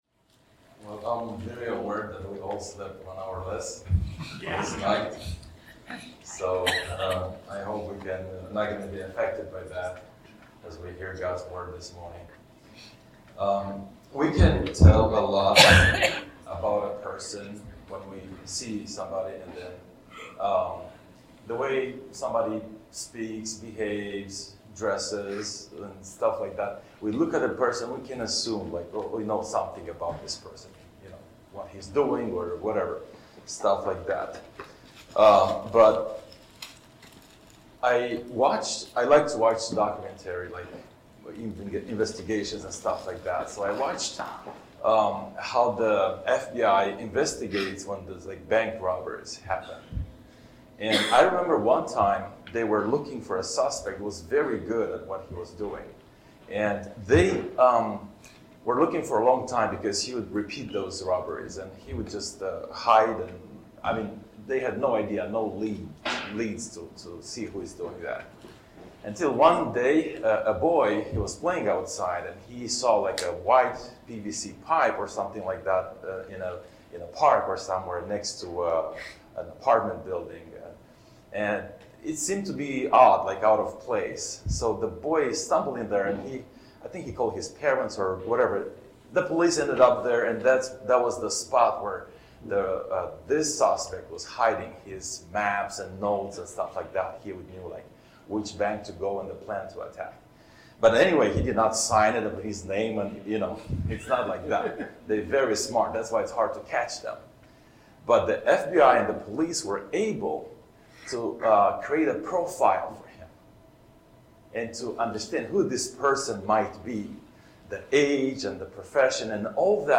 Teaching For March 9, 2025